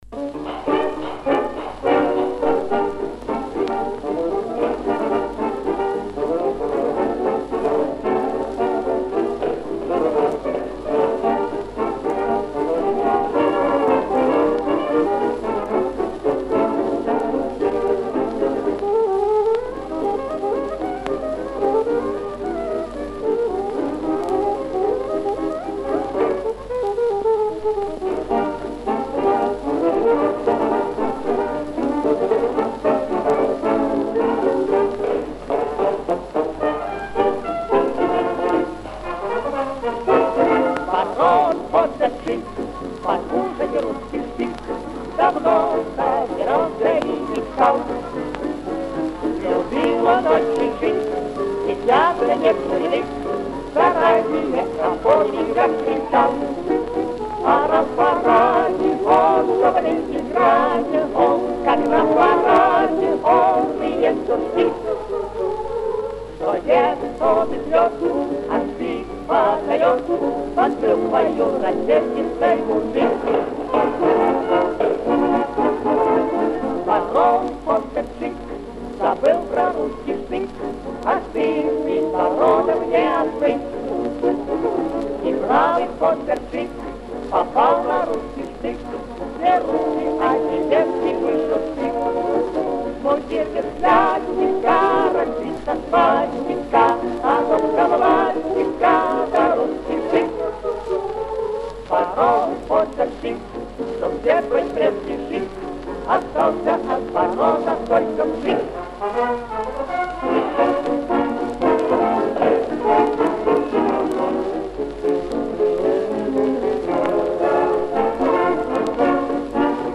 Повышение качества. Звук лучше.